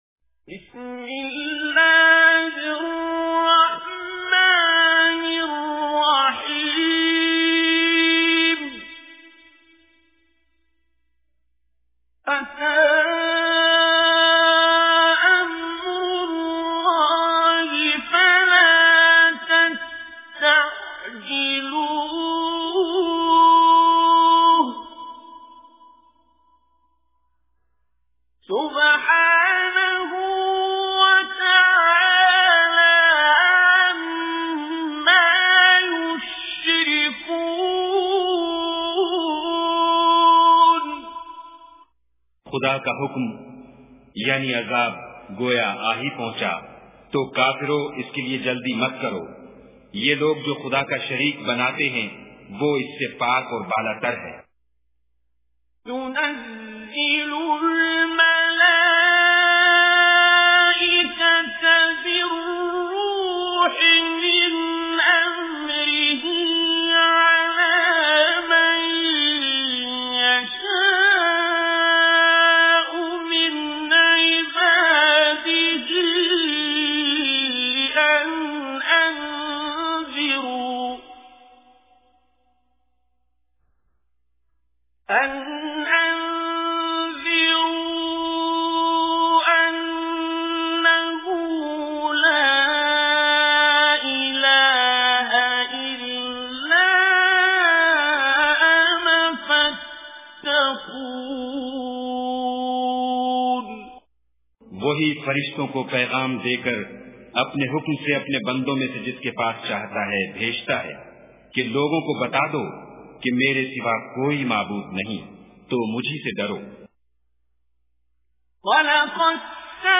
Listen online and download beautiful tilawat / Recitation of Surah An Nahl with urdu translation in the beautiful voice of Qari Abdul Basit As Samad.